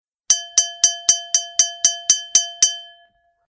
Стук ложки о бокал